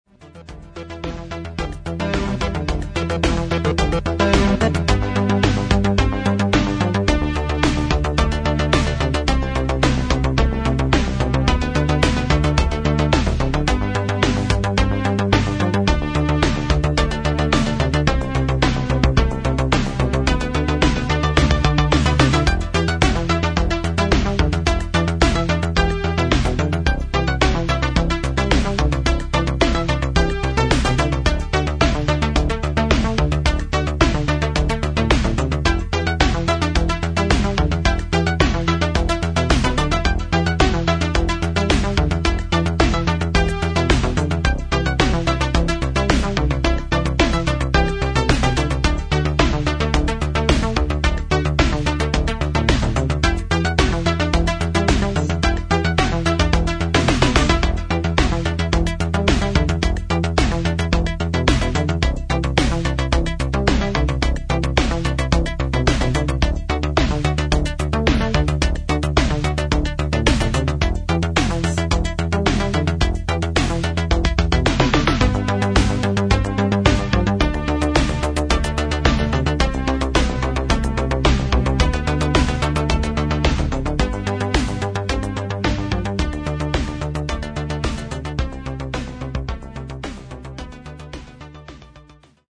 ETHNO-TECHNO, Belgrade, 1982-1986